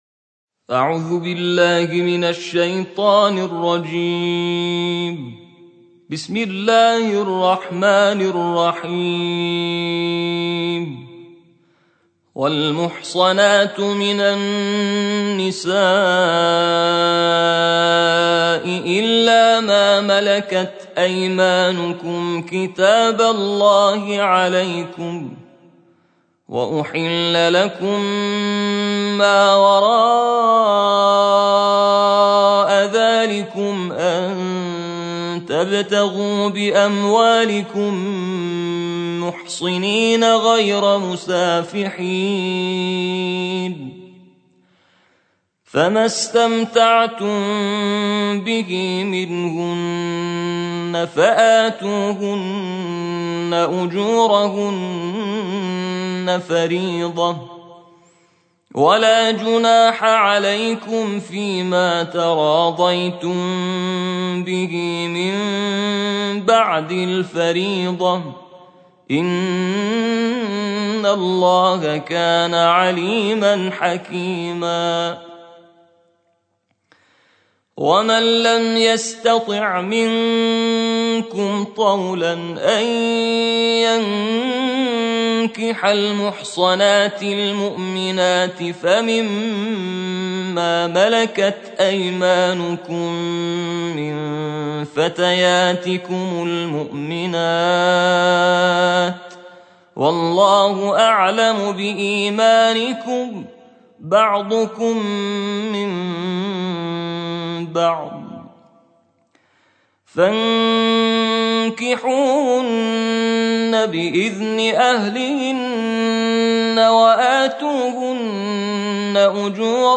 ترتیل جزء پنجم قرآن